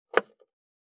509切る,包丁,厨房,台所,野菜切る,咀嚼音,ナイフ,調理音,まな板の上,料理,
効果音厨房/台所/レストラン/kitchen食器食材